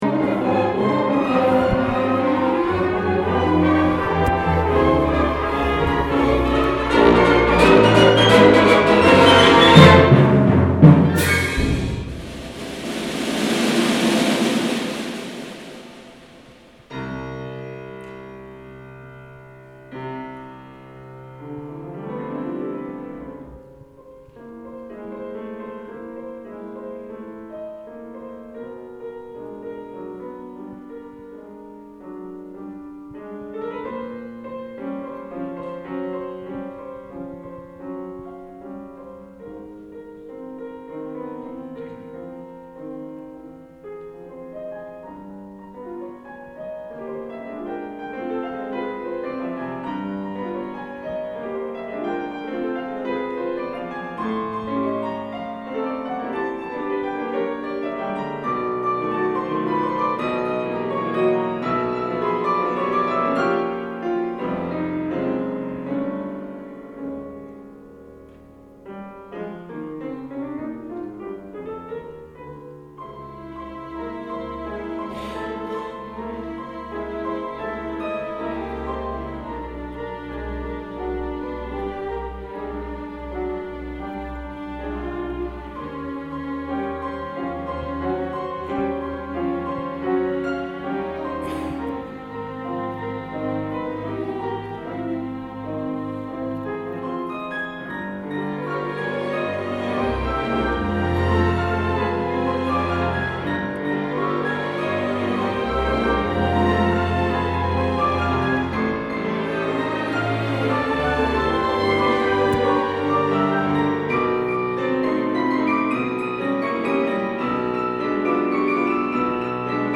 with the South Shore Symphony